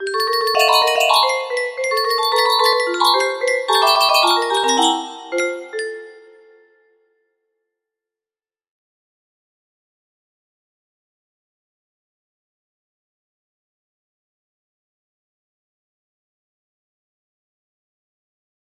music box melody
Grand Illusions 30 (F scale)